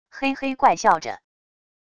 嘿嘿怪笑着wav音频